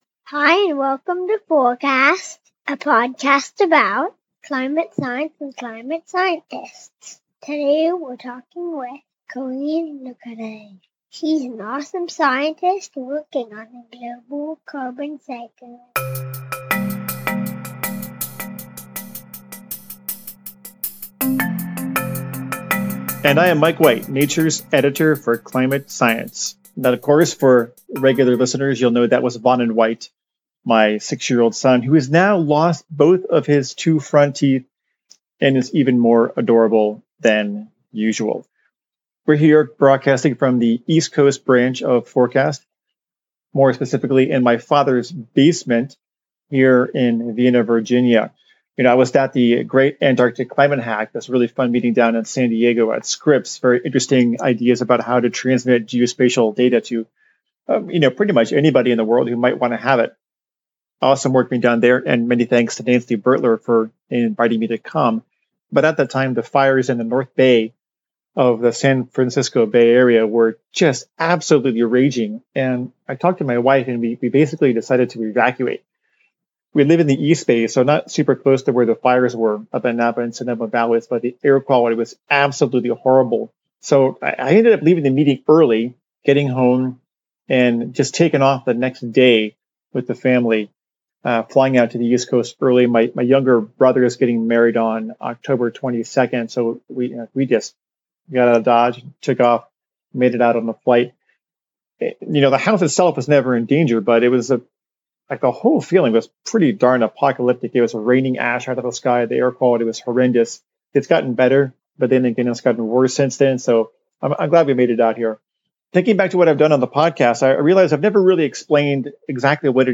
climate conversations